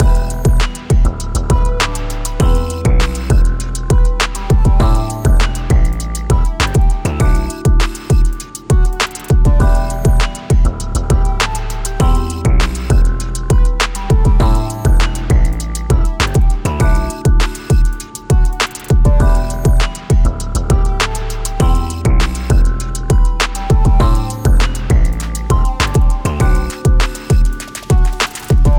Music - Song Key
E Minor